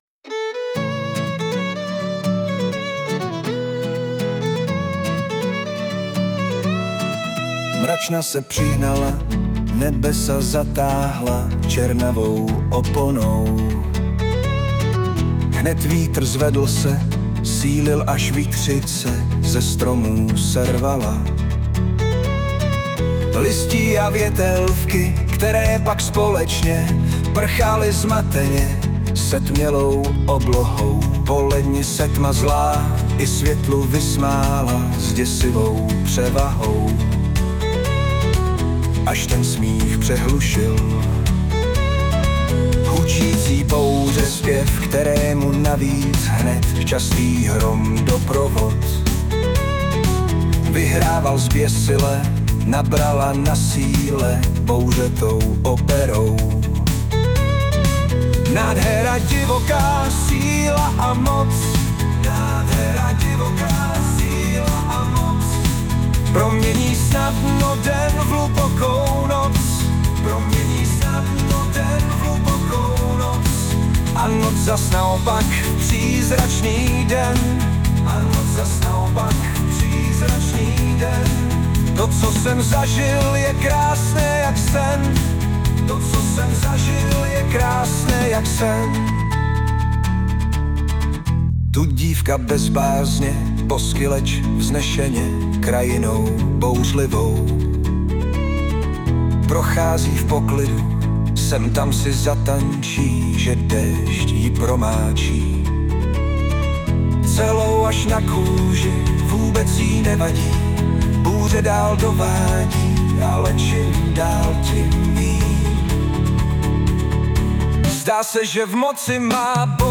ty smyčce jsou tam skvělý!!